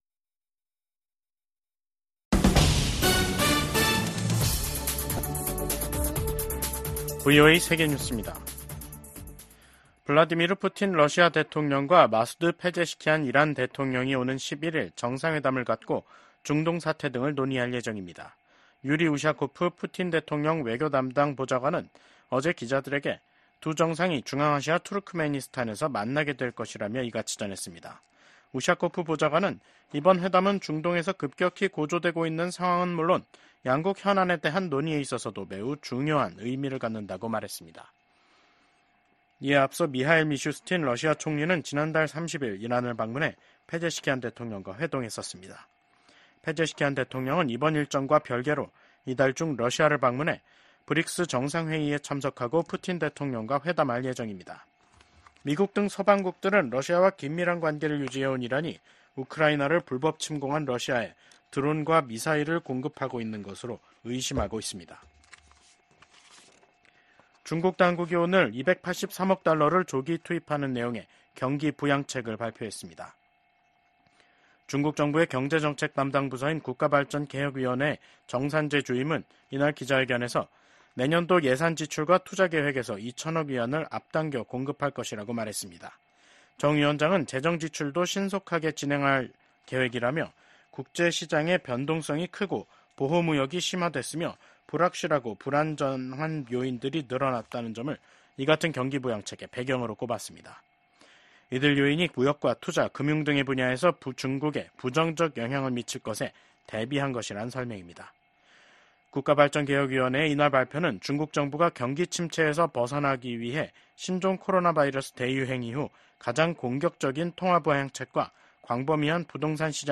VOA 한국어 간판 뉴스 프로그램 '뉴스 투데이', 2024년 10월 8일 2부 방송입니다. 김정은 북한 국무위원장은 적들이 무력 사용을 기도하면 주저없이 핵무기를 사용할 것이라고 위협했습니다. 미국 정부가 북한 해킹조직 라자루스가 탈취한 가상 자산을 압류하기 위한 법적 조치에 돌입했습니다.